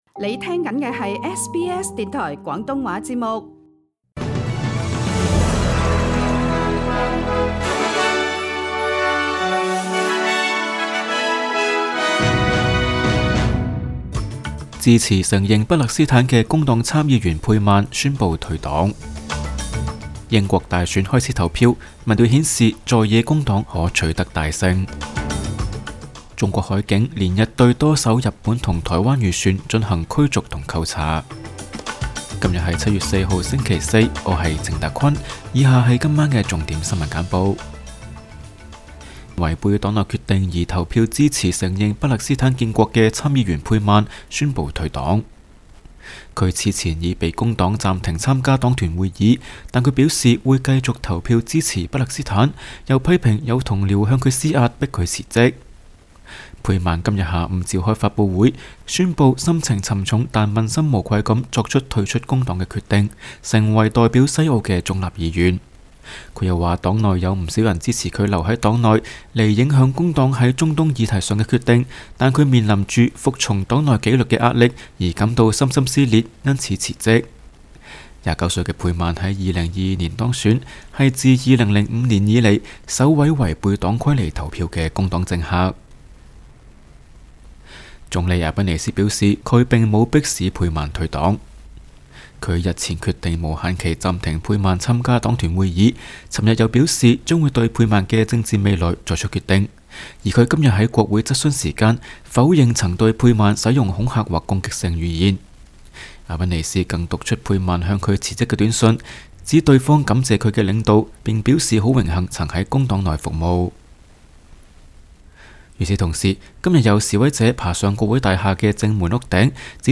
請收聽本台為大家準備的每日重點新聞簡報。
SBS 廣東話晚間新聞